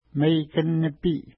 Pronunciation: meikən-nəpi:
Pronunciation